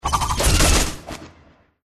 GameMpassetsMinigamesCjsnowEn_USDeploySoundGameplaySfx_mg_2013_cjsnow_attacksnow.mp3